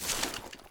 sounds / material / human / step / grass2.ogg
grass2.ogg